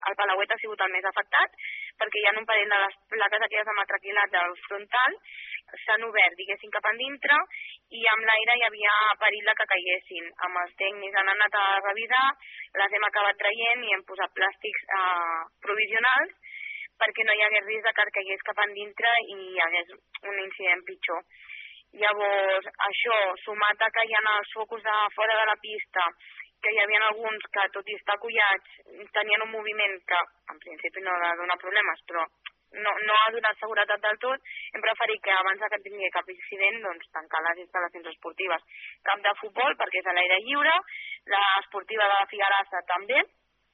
Justament el Palauet és un dels equipaments afectats pel temporal, ja que s’han desprès algunes planxes de metacrilat. Ho ha explicat Sònia González, regidora d’esports.